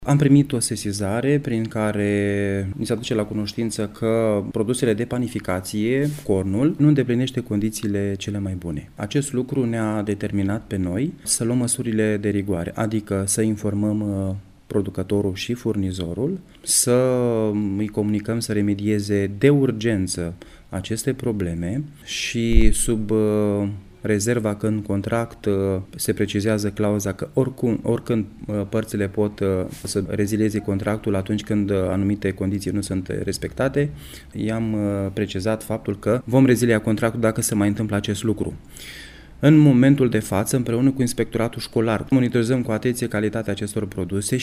Administratorul public al judeţului Vaslui, Valeriu Caragaţă: